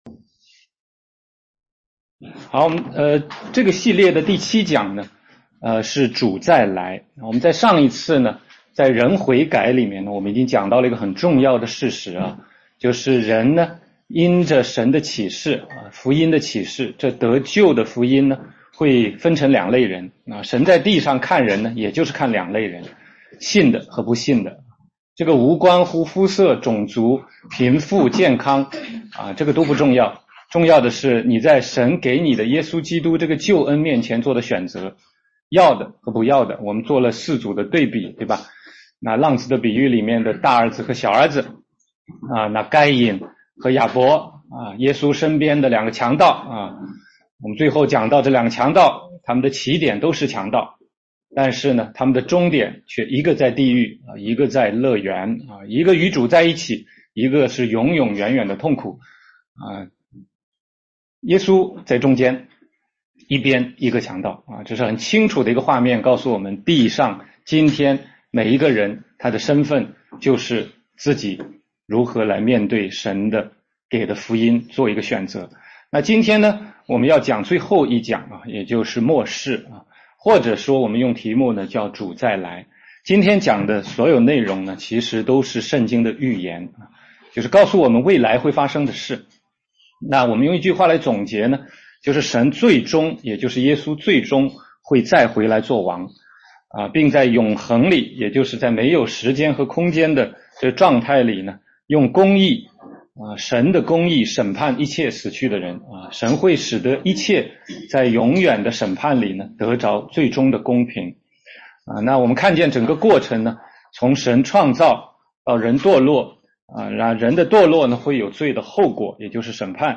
16街讲道录音 - 得救的福音第七讲：主再来